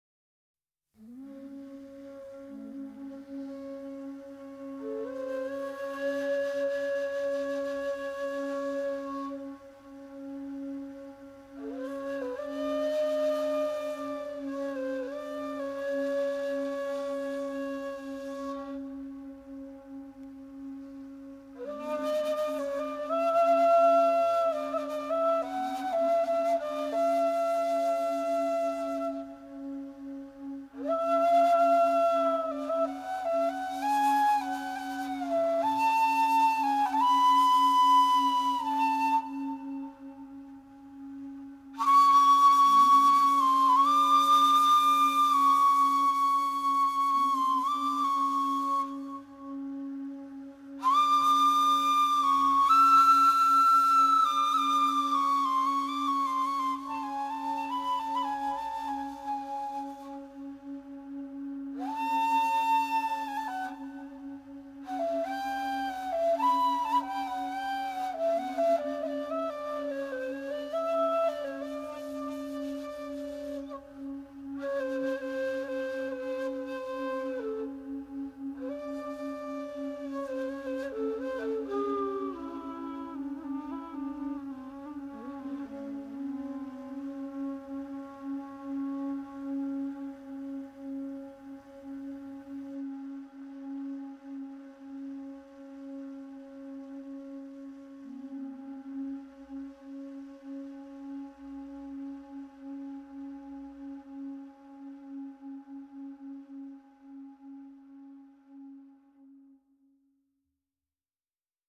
tablas.
baglama, oud, yayli tambur.
double bass.
percussion.
djembe, balafon.